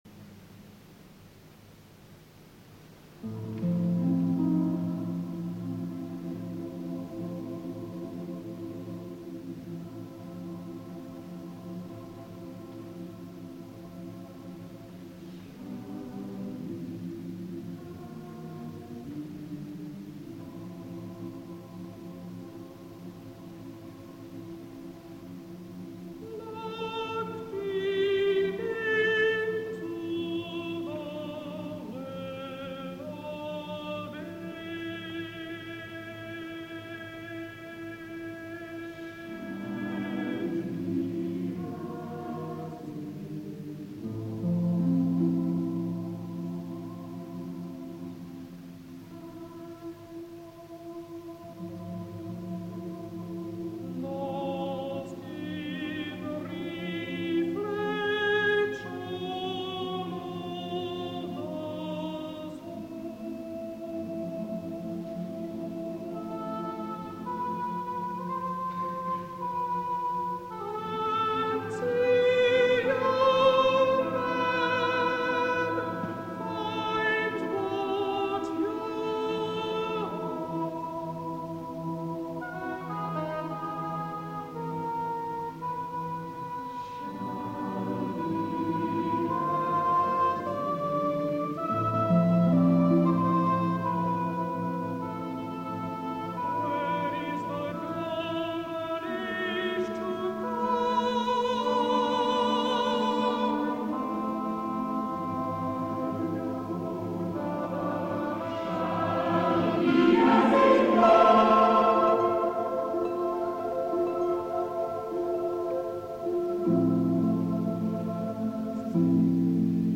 (Counter Tenor)
(Oboe d'Amore)
(Harp)